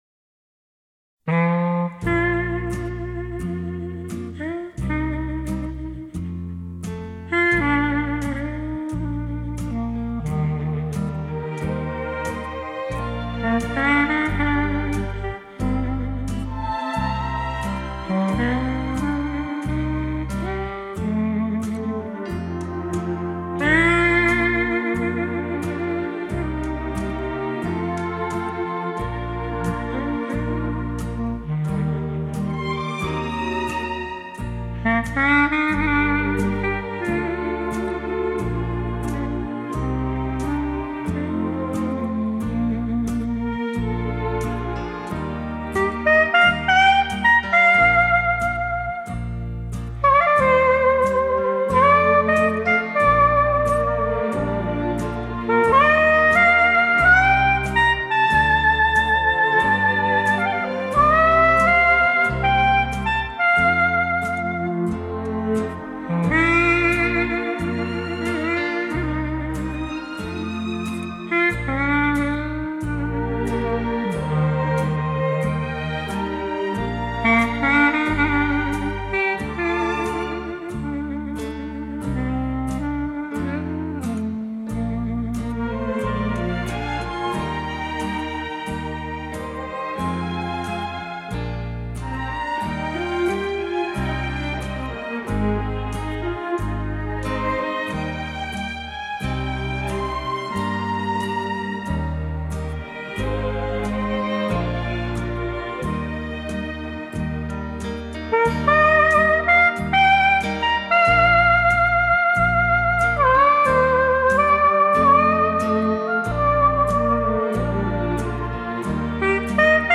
本碟堪称单簧管演奏科书，那低回、甜美、柔情而带有丝丝忧